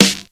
• '00s Sizzle Hip-Hop Steel Snare Drum Sample F# Key 236.wav
Royality free snare tuned to the F# note. Loudest frequency: 3171Hz
00s-sizzle-hip-hop-steel-snare-drum-sample-f-sharp-key-236-rcw.wav